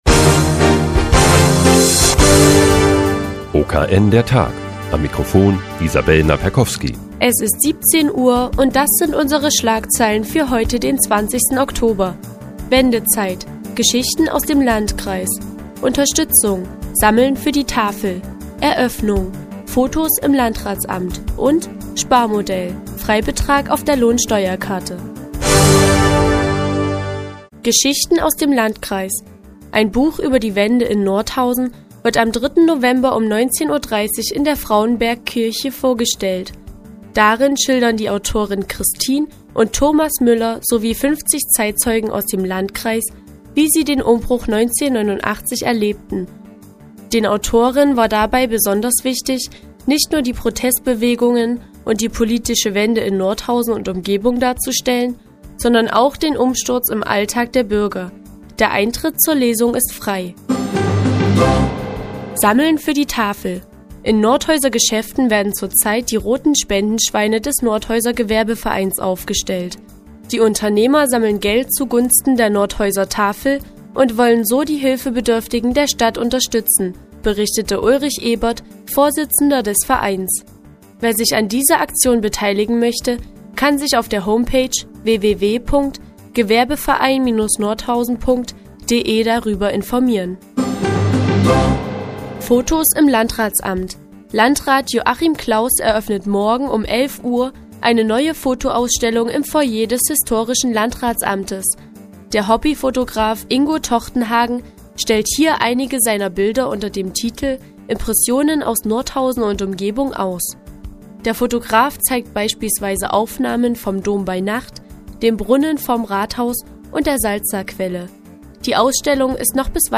Die tägliche Nachrichtensendung des OKN ist nun auch in der nnz zu hören. Heute geht es um eine Buchvorstellung in der Frauenbergkirche und eine Spendenaktion des Nordhäuser Gewerbevereins.